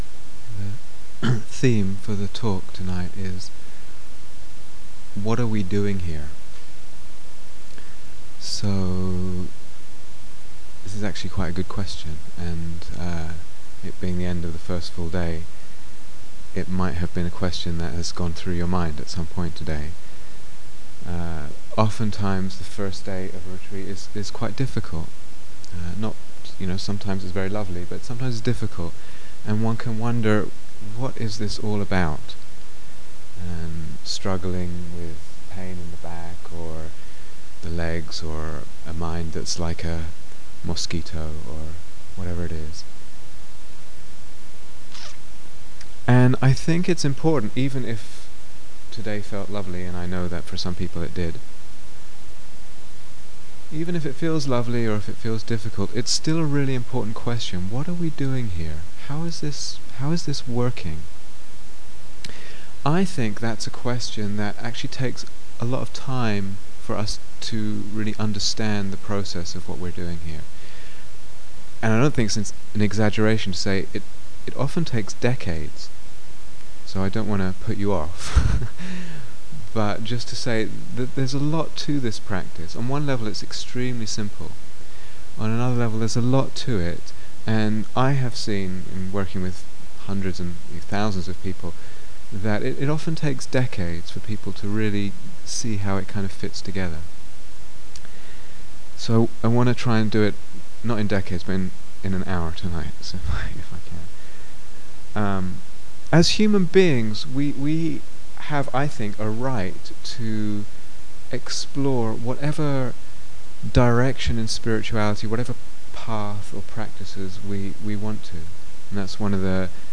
Download 0:00:00 --:-- Date 13th October 2007 Retreat/Series Silent Autumn Retreat, Finland 2007 Transcription The theme for the talk tonight is: what are we doing here?